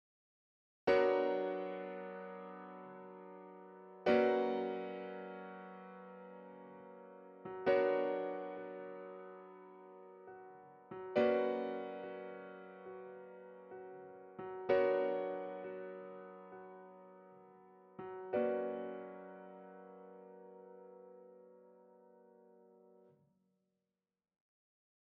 I decided to modify it a bit, cut phrases and increase the tempo.
From “And then, I waited on the dyke” on, the regularity gradually fades away with the melody becoming shattered, the rhythm growing irregular and the simple major harmony breaking into dissonances. Simultaneous F sharp major and B minor, slowly oscillating, resemble a cold wind and a dark horizon to me.